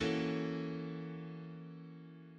Example 2: The following example illustrates the notes of an F minor triad functioning as part of a C13911 chord (C major chord with a minor 7th, minor 9th, augmented 11th, and major 13th):